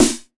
SNARE 111.wav